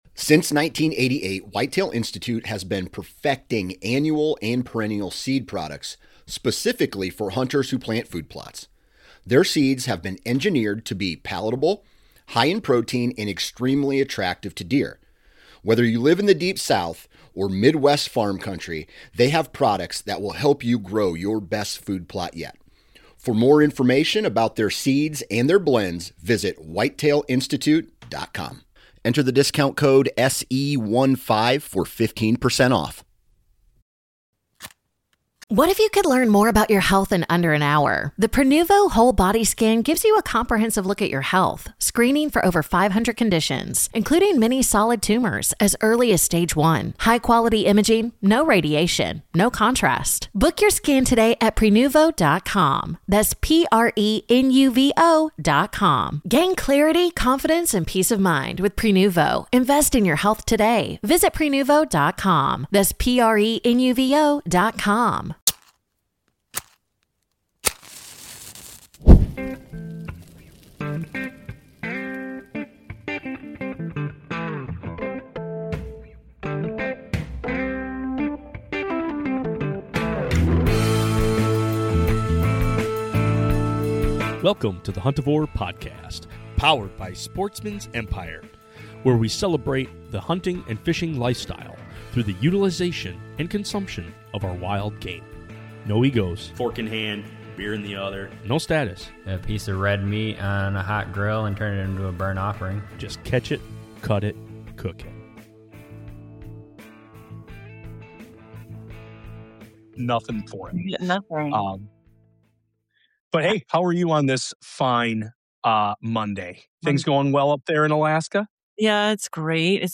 Broadcasting